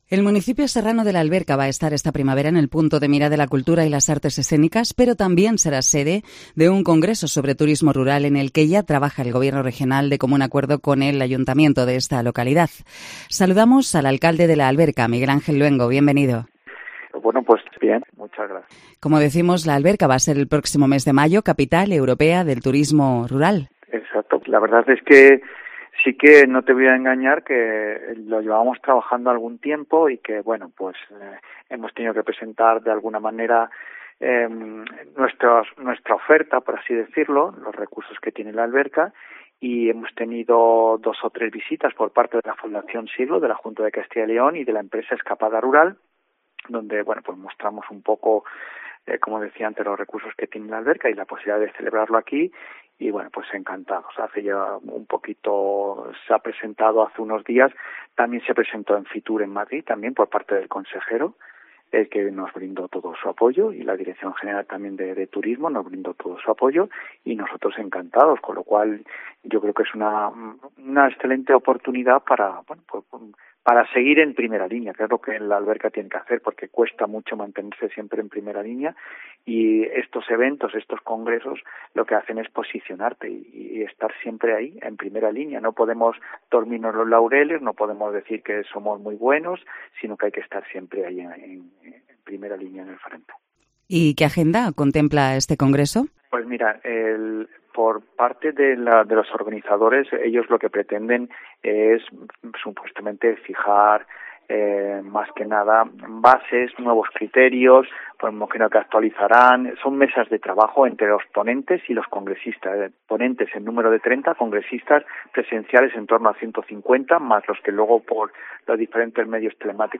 Cope Salamanca entrevista a Miguel Angel Luengo, alcalde de La Alberca